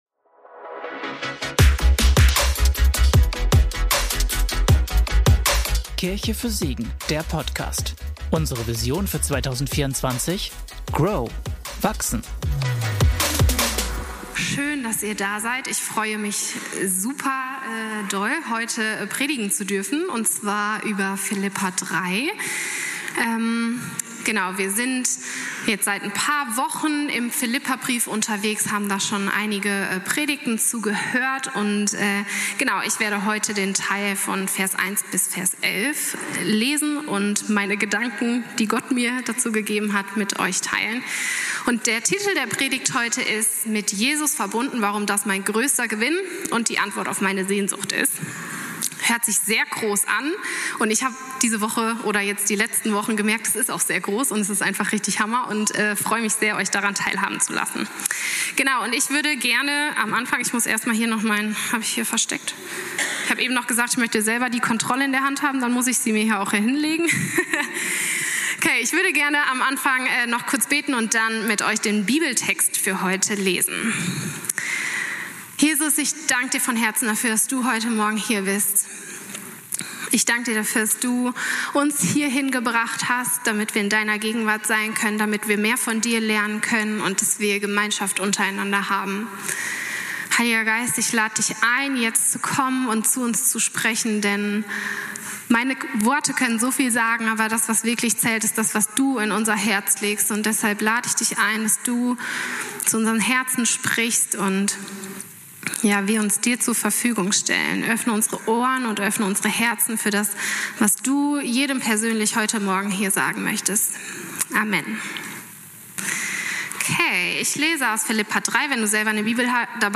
Mit Jesus verbunden - Predigtpodcast